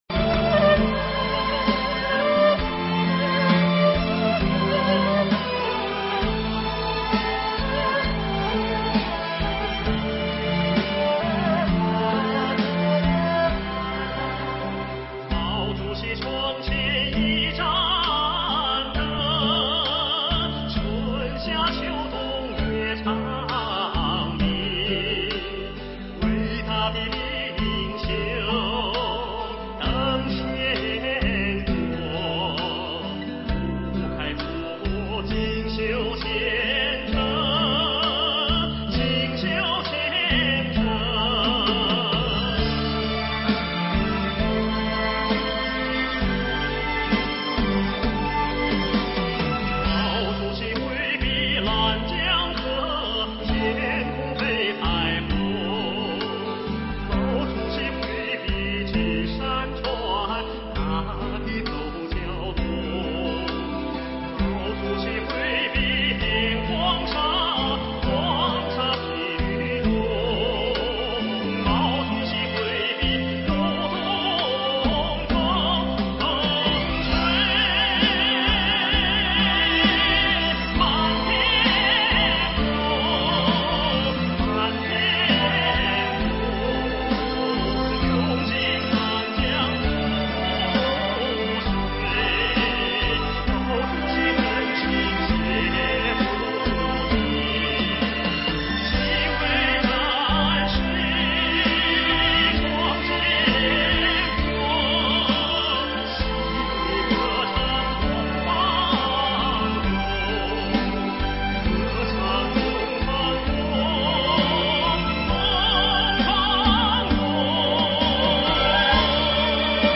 (音质稍差）